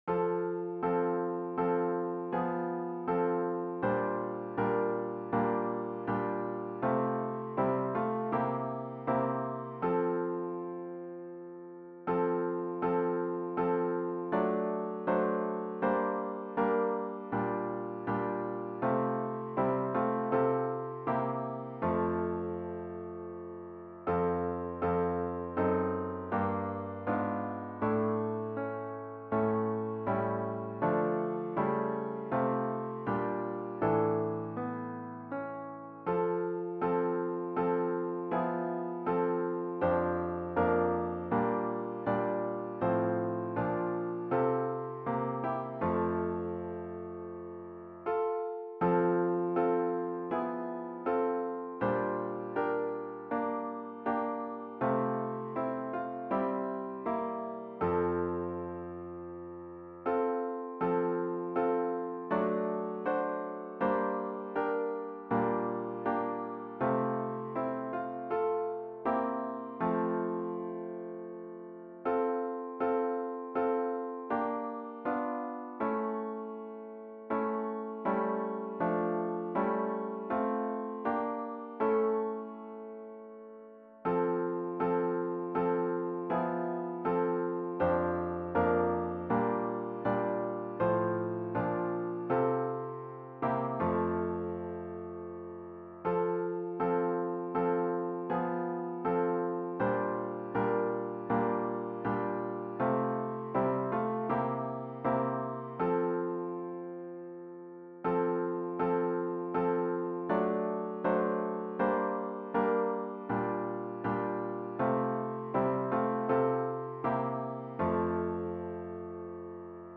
Nice flowing harmony.